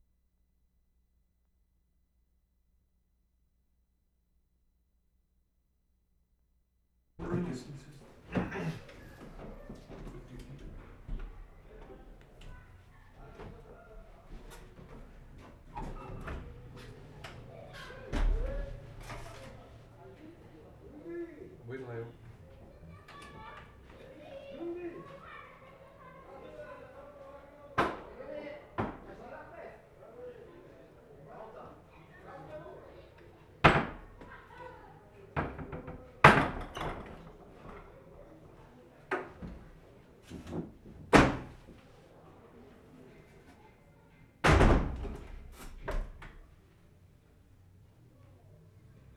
Cembra, Italy March 28/75
, 10.  SHUTTERS ON ALBERGO WINDOWS - opening and closing.